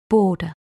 13. border (n.) /’bɔ:də/ bờ, mép, vỉa, lề (đường)